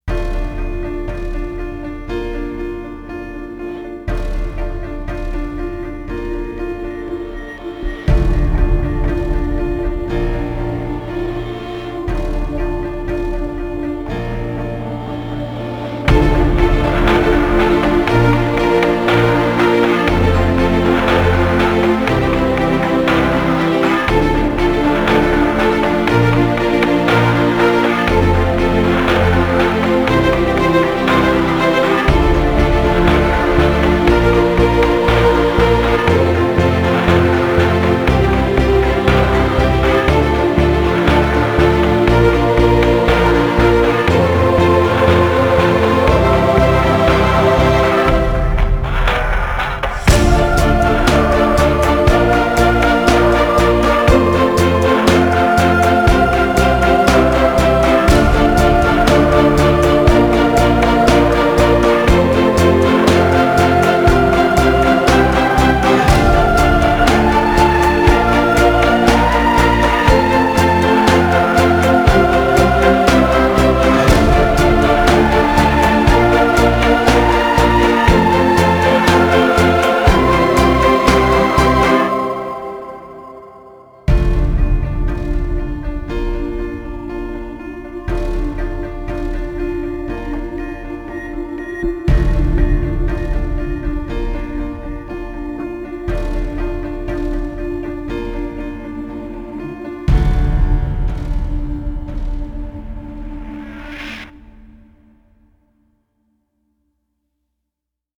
史诗气势音乐